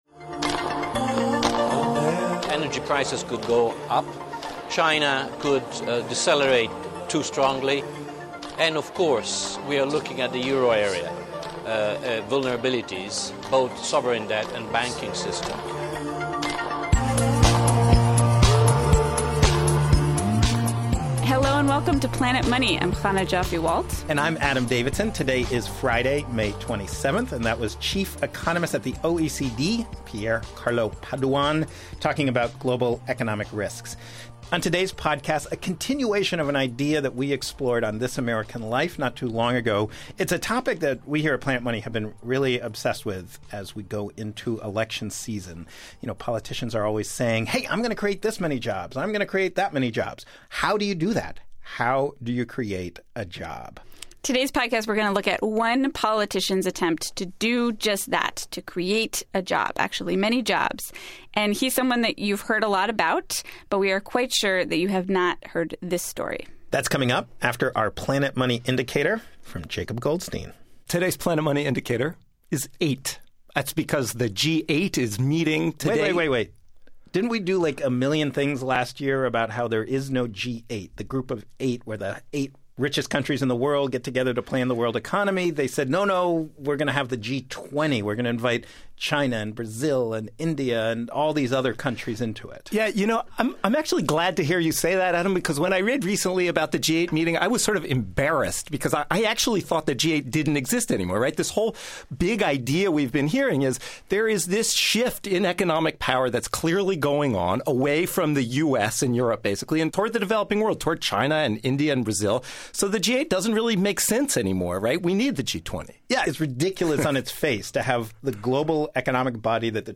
We recently traveled to Wisconsin to talk to Scott Walker and businesses around the state. Turns out, it's very hard to figure out which jobs were created because of Walker's programs, and which would have been created anyway.